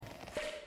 HeadInflatePOP.mp3